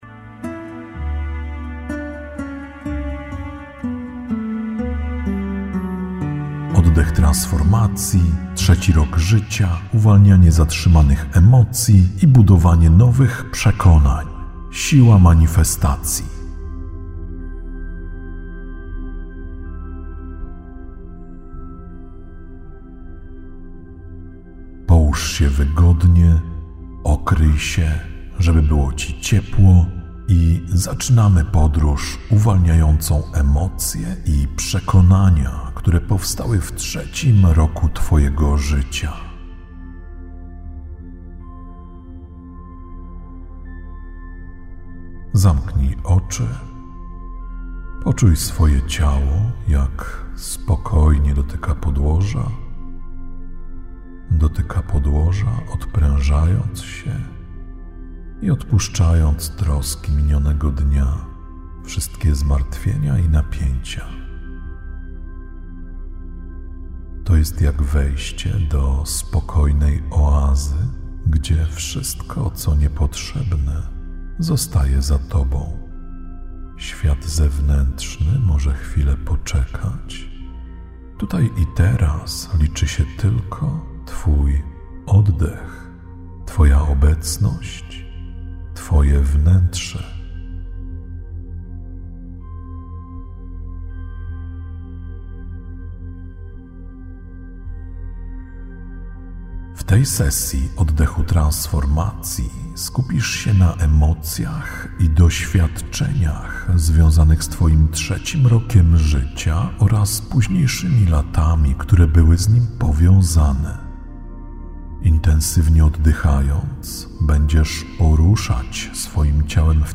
Oddech Transformacji 3 – Siła Manifestacji – godzinna sesja oddechowa
Czas trwania: 88 minut x2 Jakość: Hi-Fi 224 kbps Rozmiar: 141 MB + 179 MB (2 pliki ZIP – dwie wersje) Zawiera lektora: Tak Zalecane słuchawki: TAK Rok wydania: 2025 Instrukcja PDF: Tak